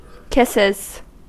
Ääntäminen
Ääntäminen US : IPA : [ˈkɪs.ɪz] Haettu sana löytyi näillä lähdekielillä: englanti Käännöksiä ei löytynyt valitulle kohdekielelle. Kisses on sanan kiss monikko.